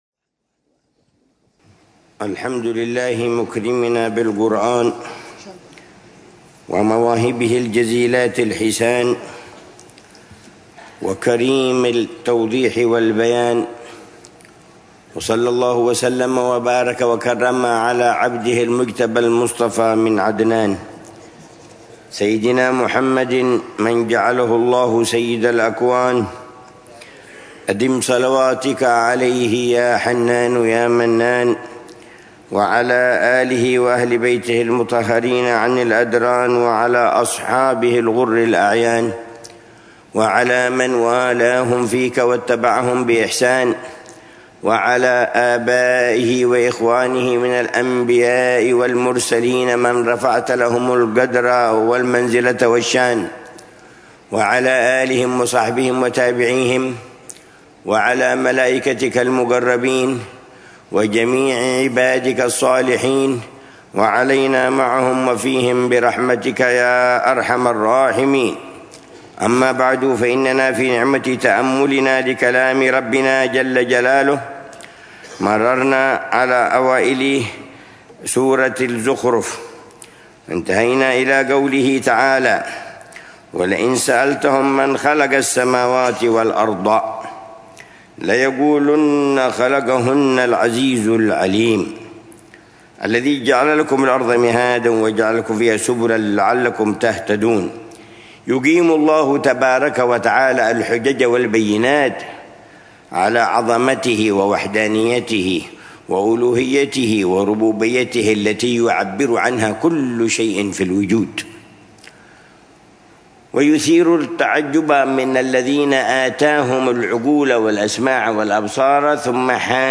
الدرس الثاني من تفسير العلامة عمر بن محمد بن حفيظ للآيات الكريمة من سورة الزخرف، ضمن الدروس الصباحية لشهر رمضان المبارك من عام 1446هـ